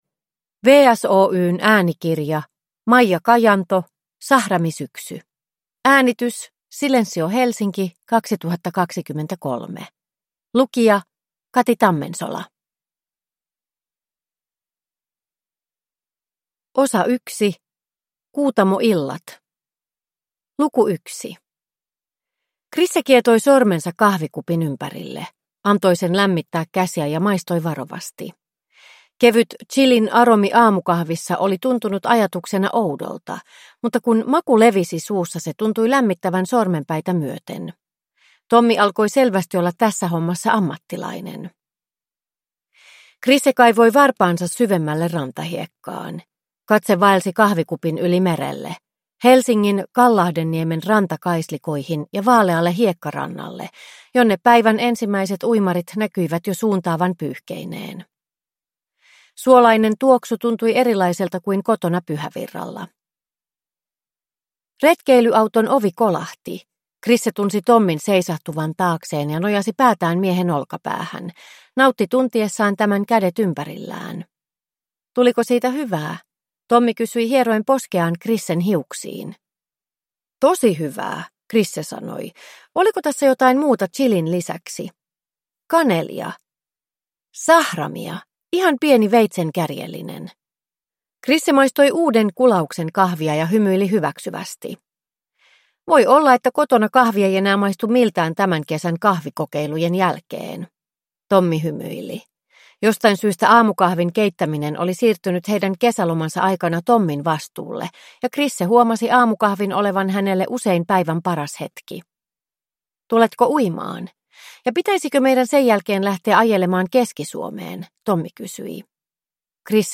Sahramisyksy – Ljudbok – Laddas ner